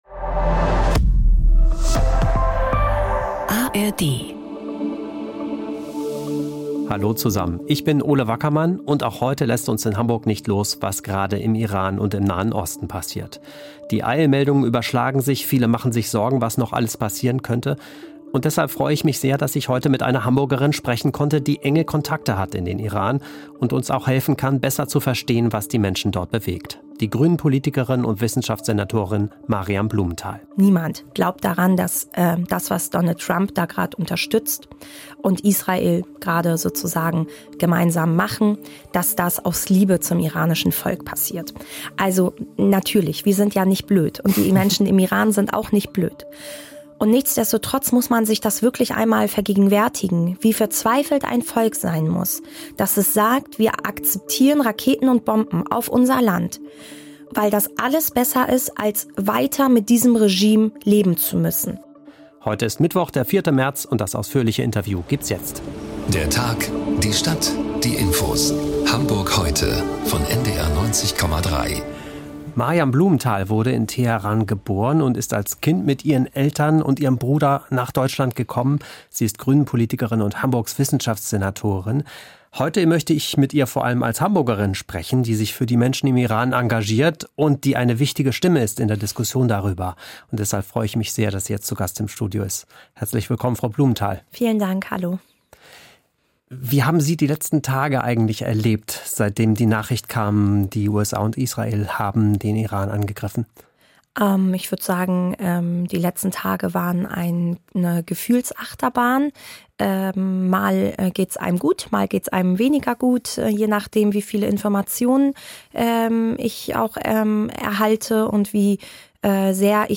Moin, in dieser Folge spreche ich ausführlich mit Hamburgs Wissenschaftssenatorin Maryam Blumenthal.